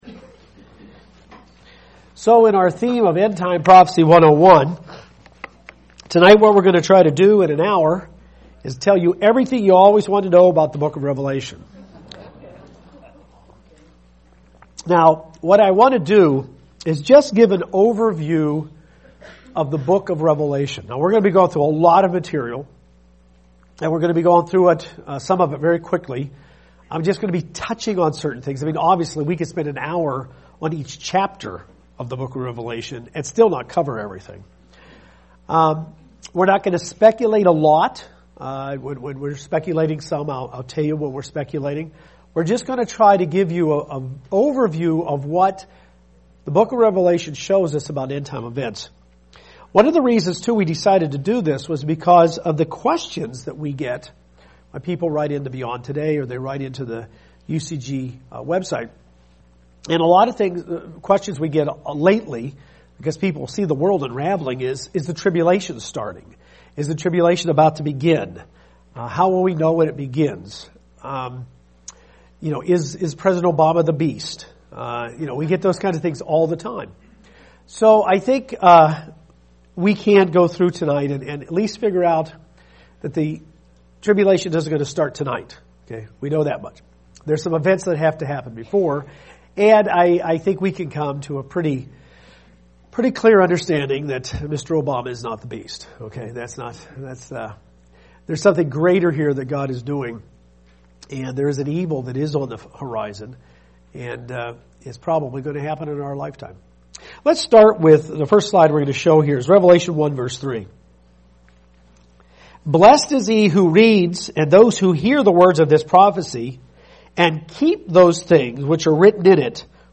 This is the first part in the Bible study series: End-Time Prophecy 101.